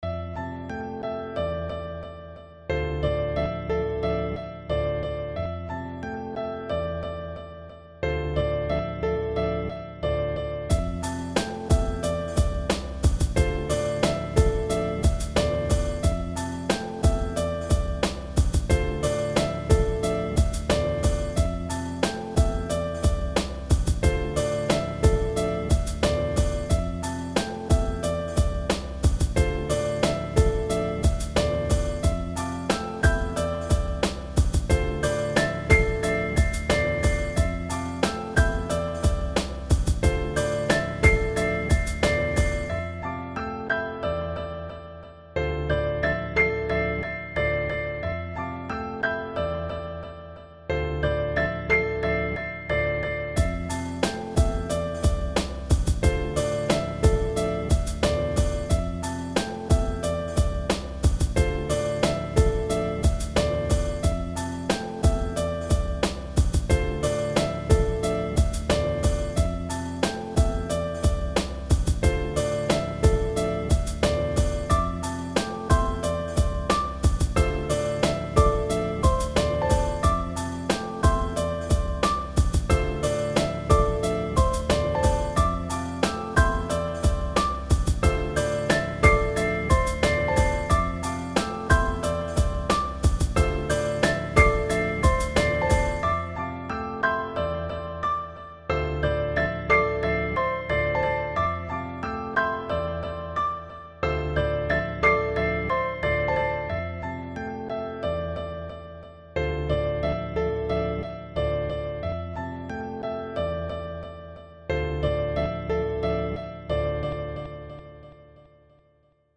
약간 짧은 감이 있어요~ 잘때 듣기 좋은 노래 같네요 ^^ « Prev List Next »